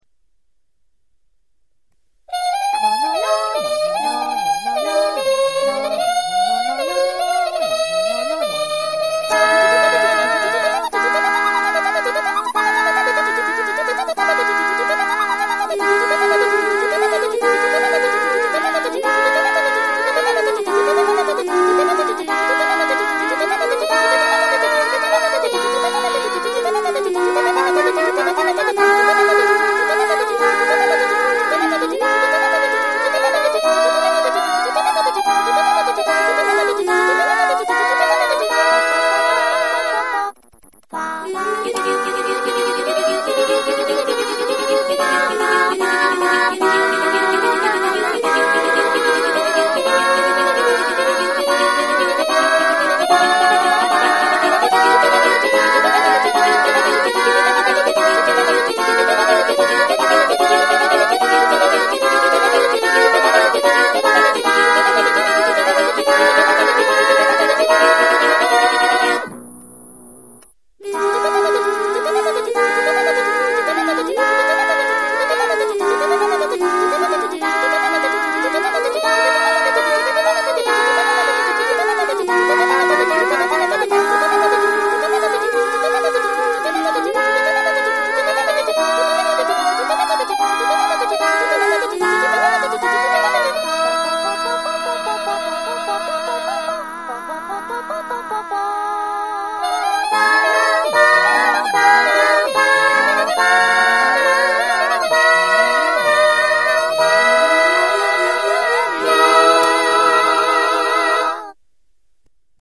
パートのボリュームバランスいくつか間違ってる感がバリバリですｗ
最後のBPM変化は割と適当な感じです、手抜きですいません…